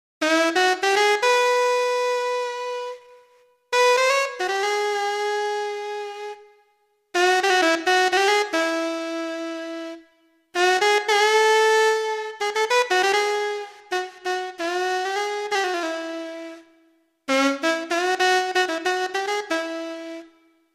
Софтовый синтезатор также заметно шумит.
Vl-инструмент носит название Lite Alto.
А: Звук S-YXG100 с жесткой атакой на данном фрагменте оставляет неприятное впечатление.